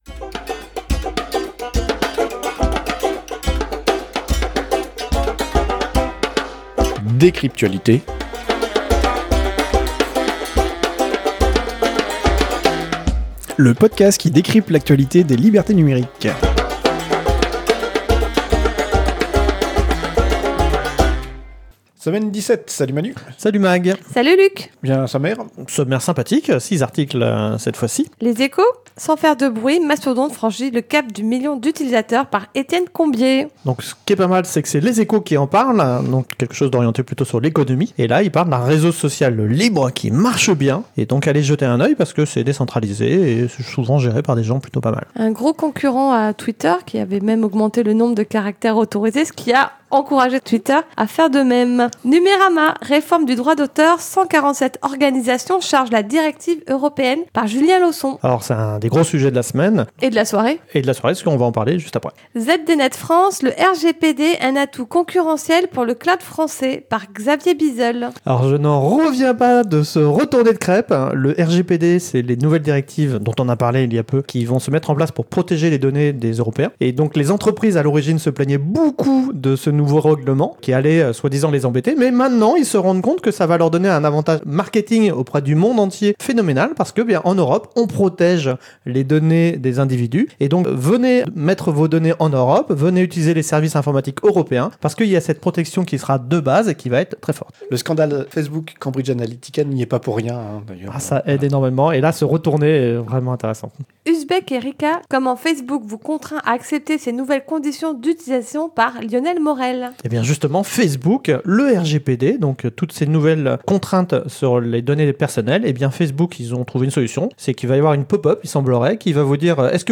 Studio d’enregistrement